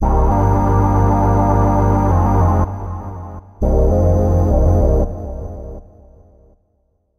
Tag: 100 bpm Chill Out Loops Organ Loops 3.23 MB wav Key : Unknown